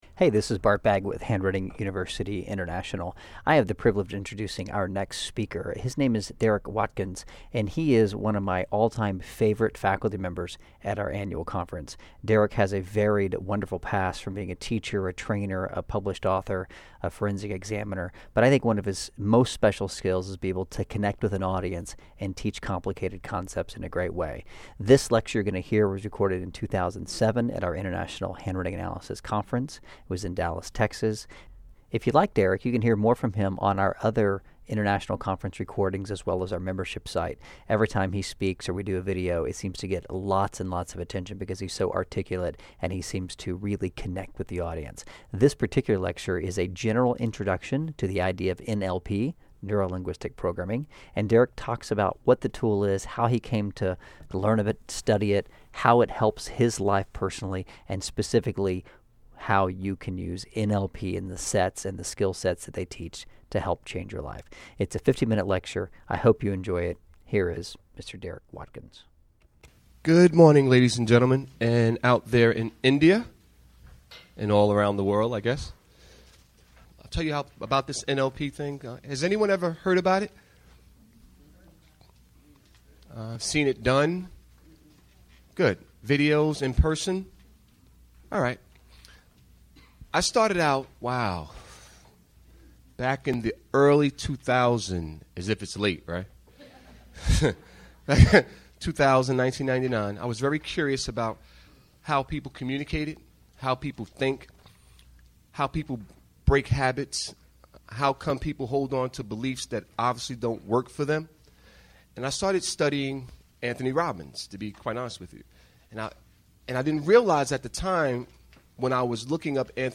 49 Minute Lecture
Recorded Live at the 2007 International Handwriting Analysis Conference.